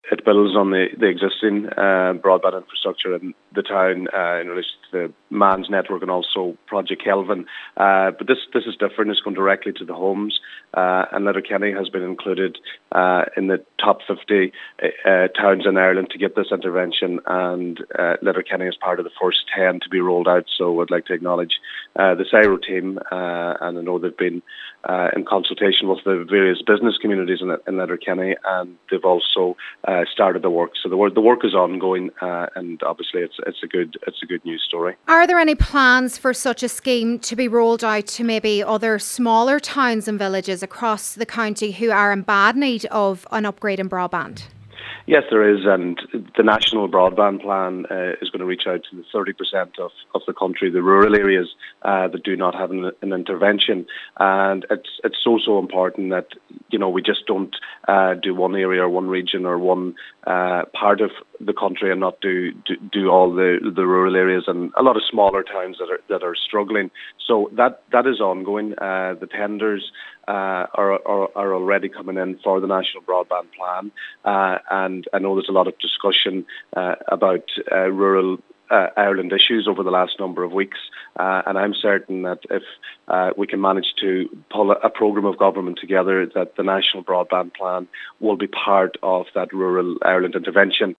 Speaking from the launch Deputy Joe McHugh said plans are also in motion to improve broadband to rural areas in Donegal: